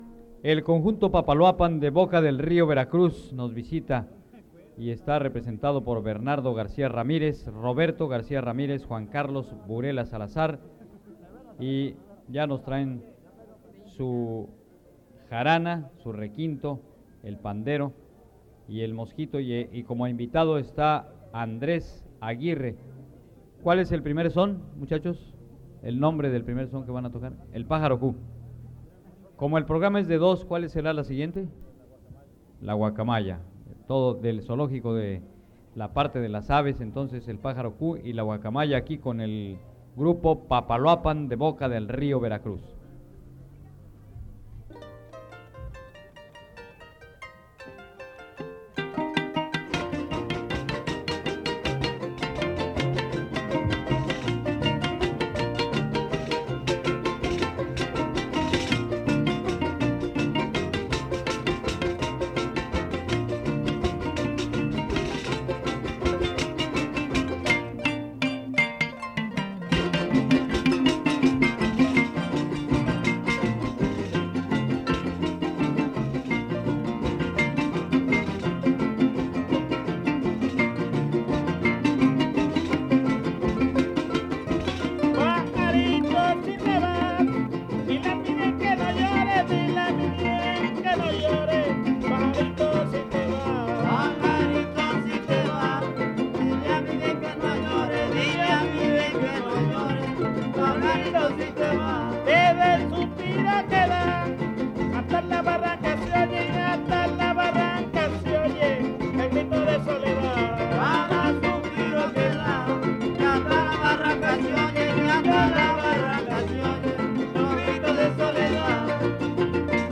• Papaloapan (Grupo musical)
Noveno Encuentro de jaraneros